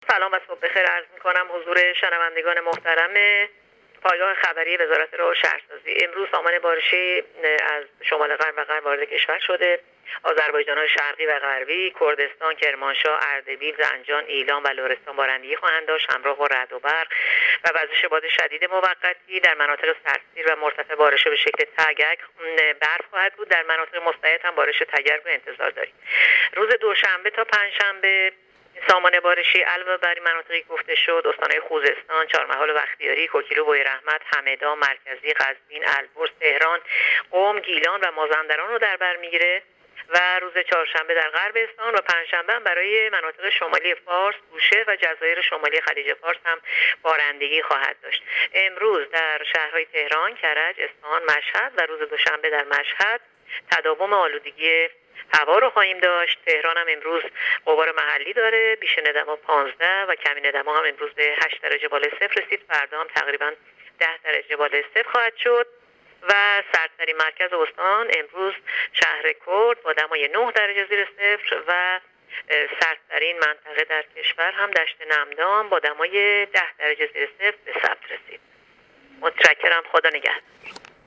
گزارش رادیو اینترنتی پایگاه‌ خبری از آخرین وضعیت آب‌وهوای ۱۶ آذر؛